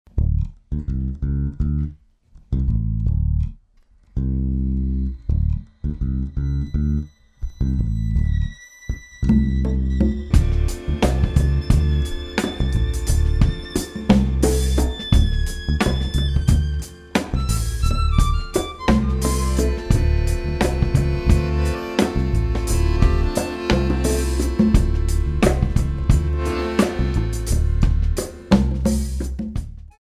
Medium Tempo